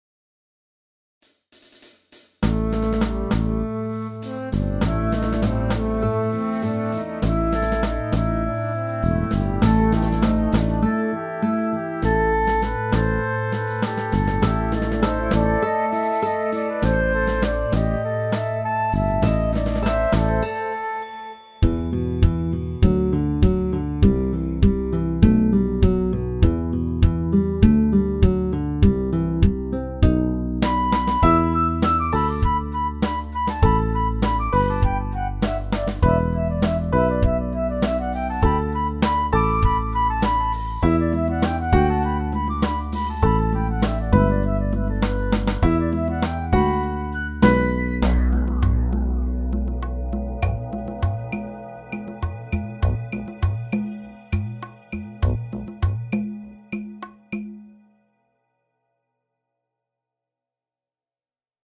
Sound icon I made this short little piece with Garage Band as I was getting started.